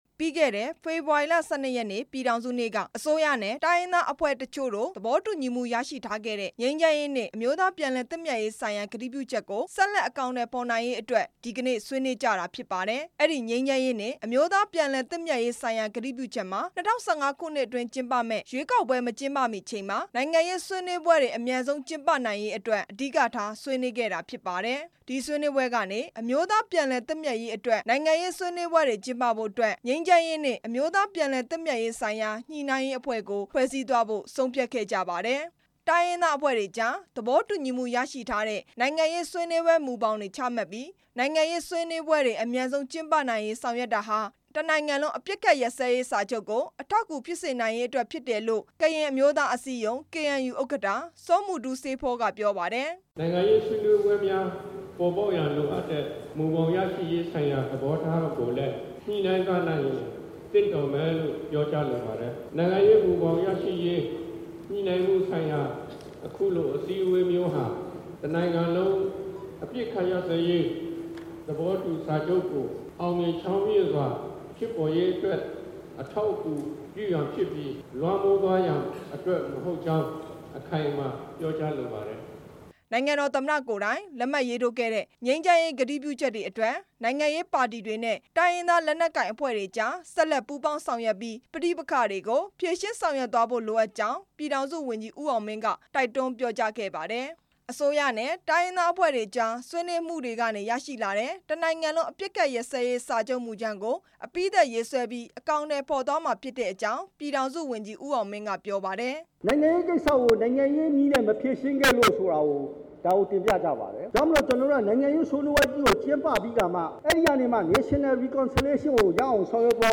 ငြိမ်းချမ်းရေးနဲ့ အမျိုးသား ပြန်လည်သင့်မြတ်ရေး ဆွေးနွေးပွဲ အကြောင်းတင်ပြချက်